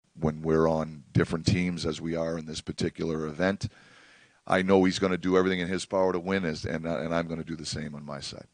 Reflecting on Saturday’s game against Team Canada and the possibility of facing the Canadians in the championship on Thursday, Sullivan says any team led by Sidney Crosby is going to be tough.